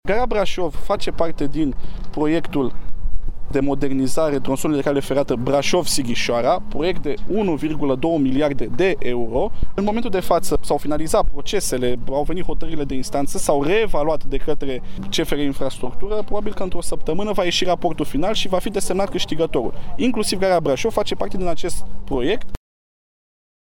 Ministrul Transporturilor Răzvan Cuc a venit la Brașov ca să verifice stadiul lucrărilor la Autostrada București – Brașov, Tronson Comarnic – Brașov.